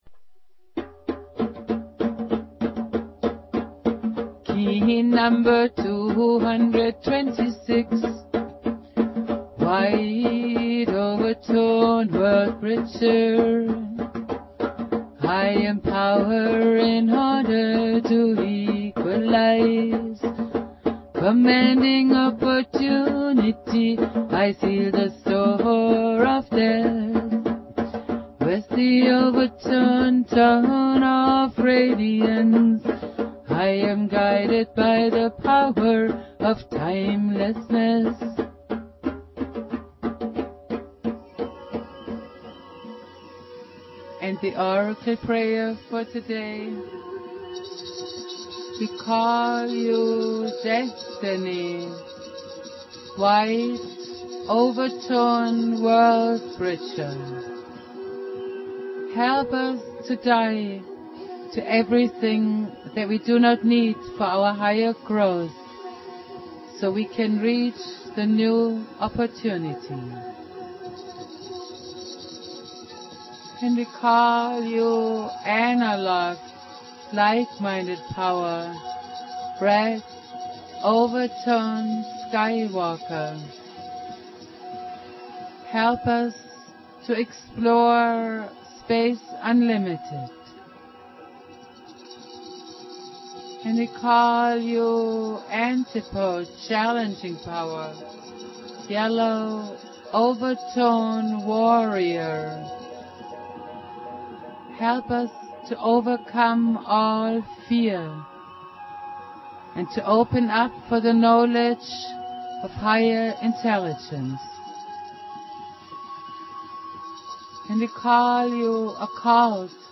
playing flute
Prayer